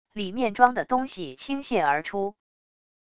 里面装的东西倾泻而出wav音频